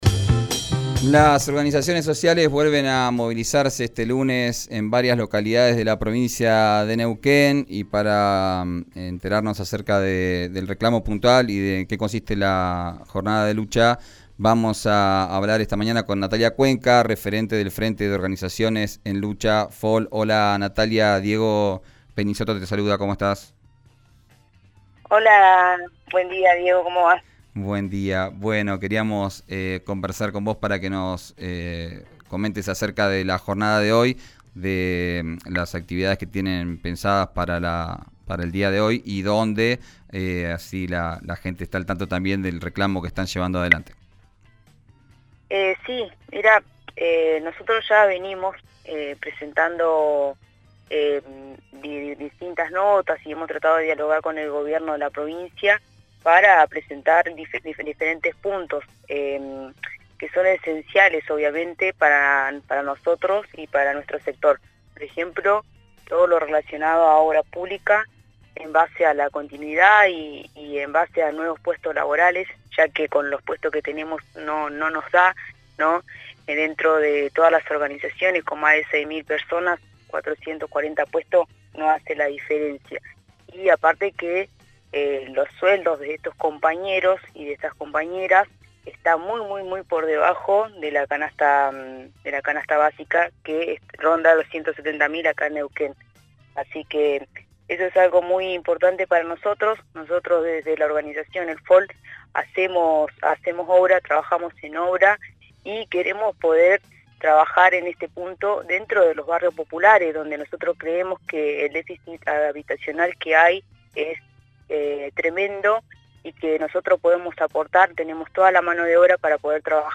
En diálogo con «Arranquemos», por RÍO NEGRO RADIO, sostuvo que de 6 mil personas que integran las organizaciones sociales en Neuquén, «440 puestos no hace la diferencia».